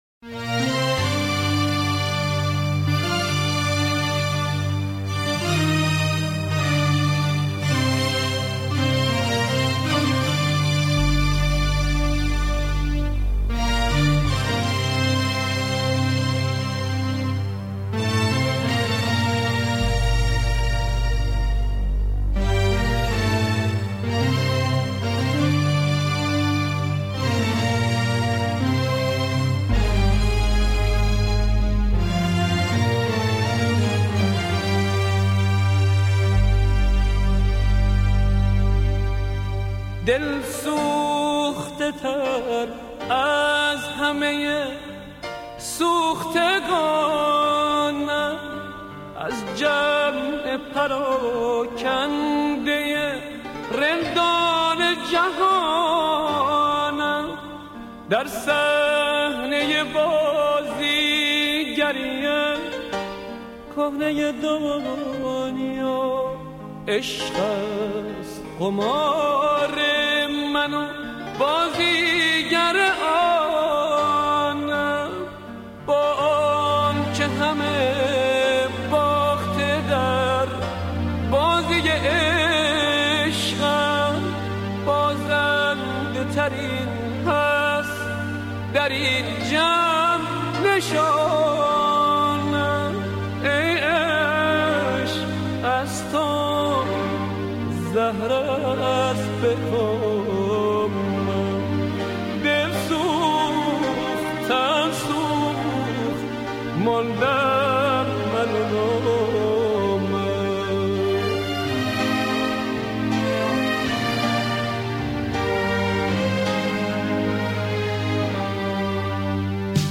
سوزناک ترین آهنگی که تا حالا شنیده ام